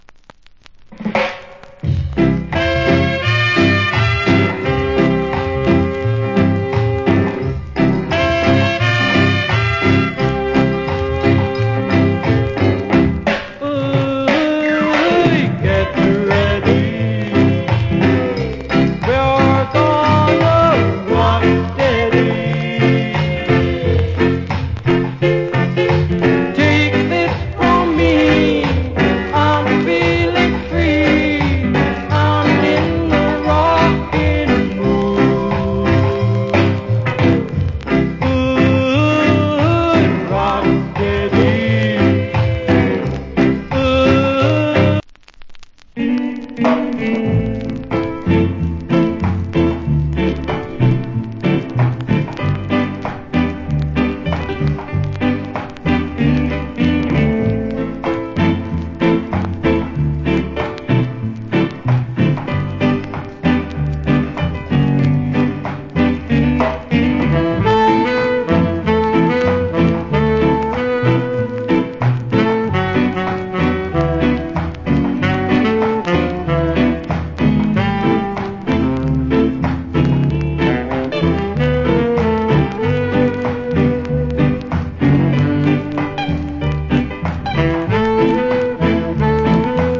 Great Rock Steady.